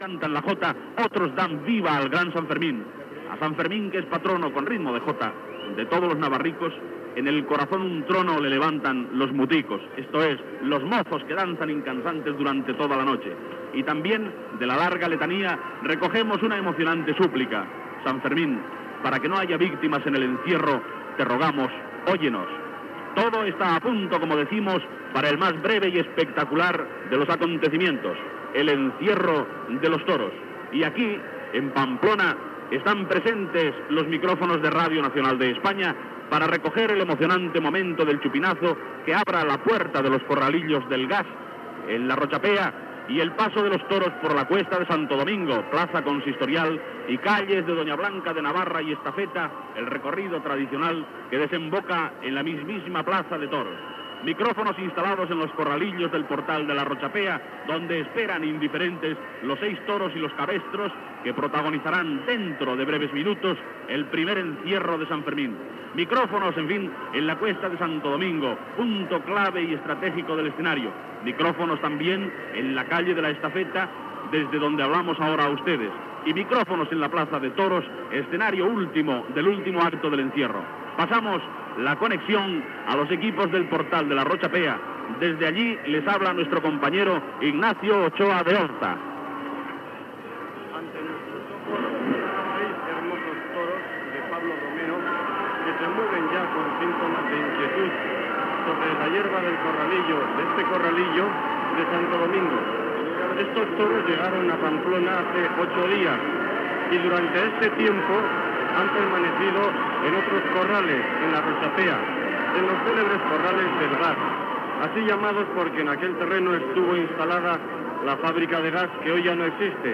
Senyals horaris, hora i narració de la correguda dels braus pels carrers de Pamplona i la seva plaça de braus. Gènere radiofònic Informatiu